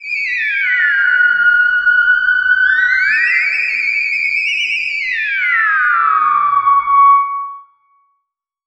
Theremin_Swoop_07.wav